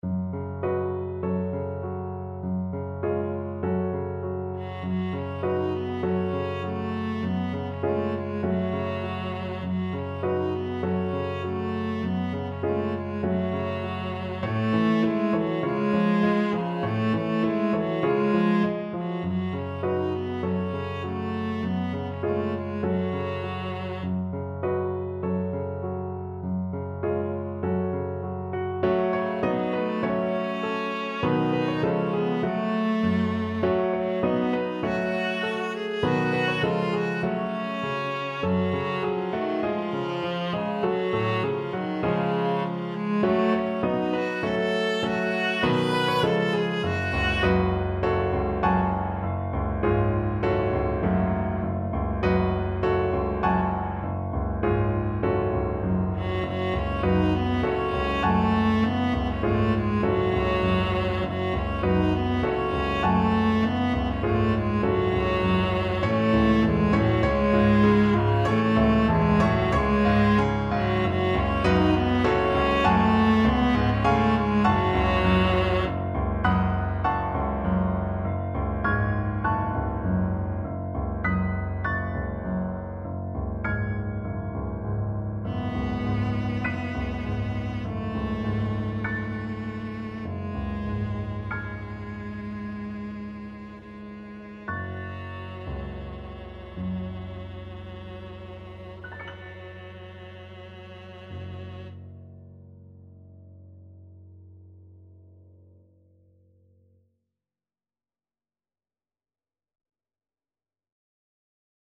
Viola
F# minor (Sounding Pitch) (View more F# minor Music for Viola )
2/4 (View more 2/4 Music)
Moderato
sing_we_now_VLA.mp3